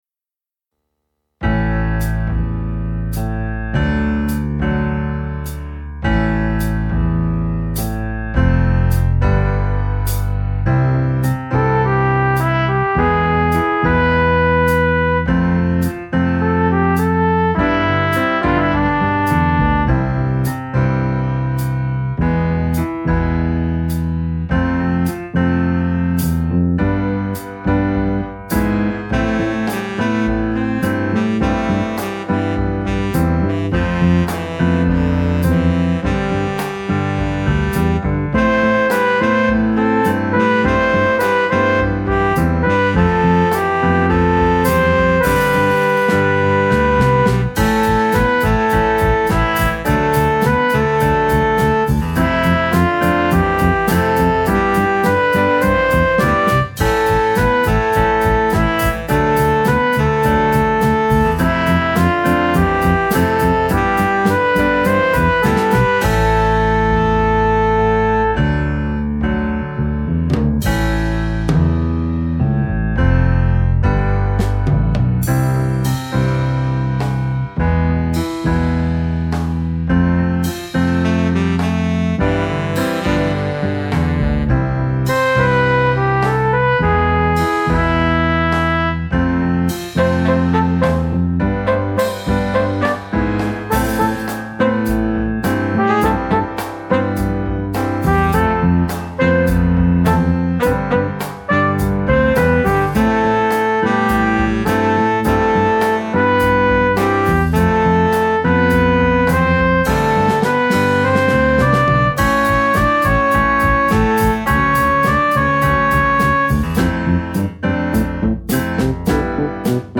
minus Instrument 2